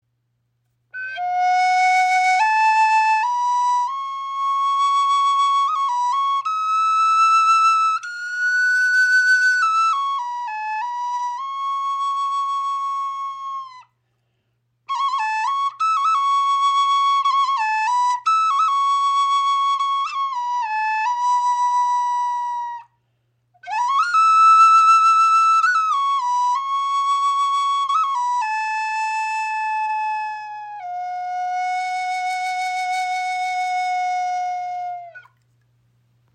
• Icon Klarer, heller Klang mit warmer Note
Kestrel Flöte in Fis-Moll | Aromatische Zeder | hell, klar, lebendig
Klar im Klang, kompakt in der Form und vielseitig spielbar.
So entsteht ein warmer, voller und resonanter Klang, der tief berührt.